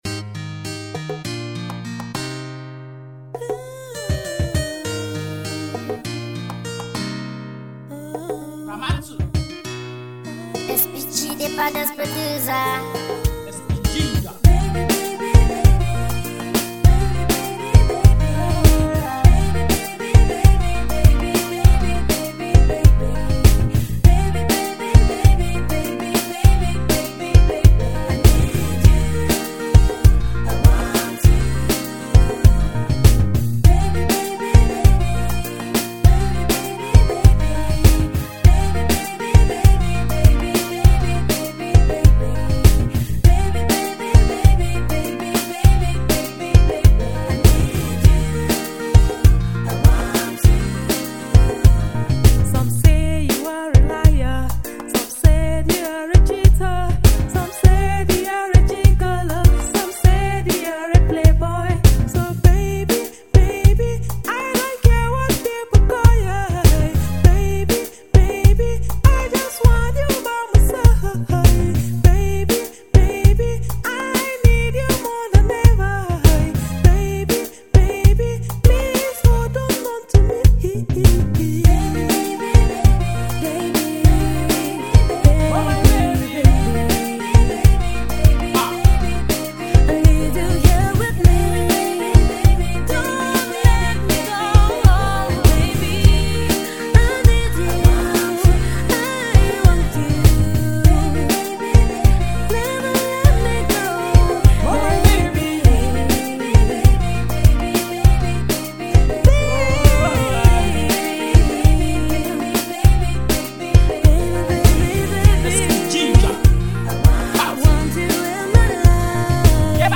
One of your Nigeria female artist